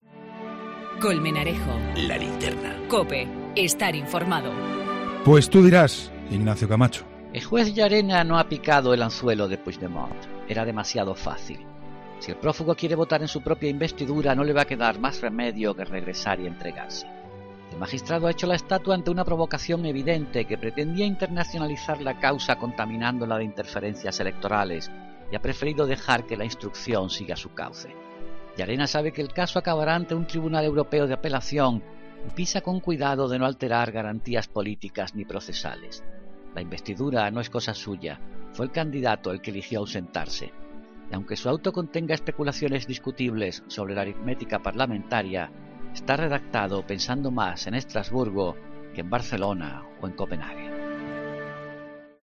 Ignacio Camacho comenta en 'La Linterna' la actuación del juez Llarena respecto a los viajes de Puigdemont por Europa.